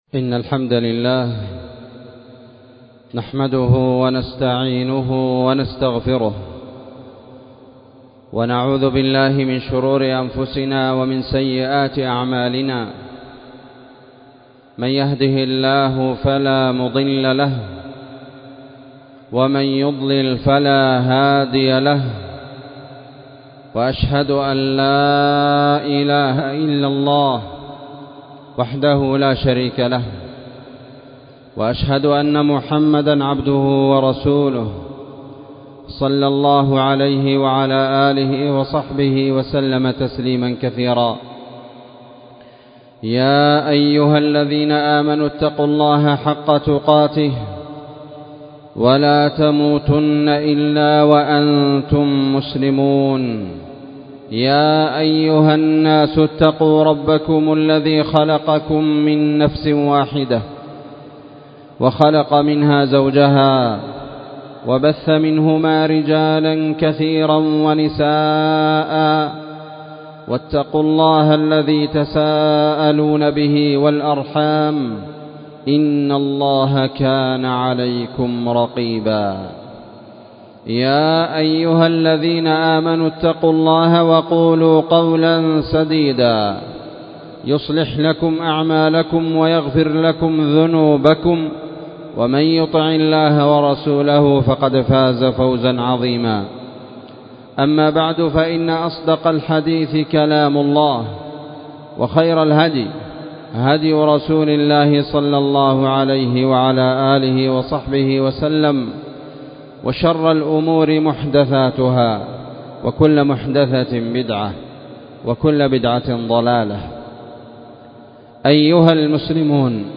خطبة
والتي كانت في مسجد المجاهد- النسيرية- تعز